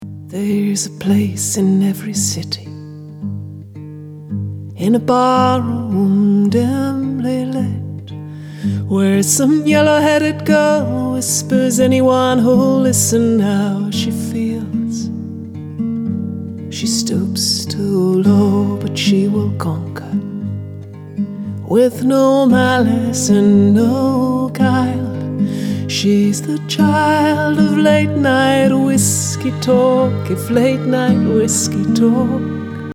et un deuxième exemple avec des réglages un peu différents pour bétonner et resserrer le signal - ça va déjà plus vers le réglage limiter ==>>comp+comp 2
double_comp2.mp3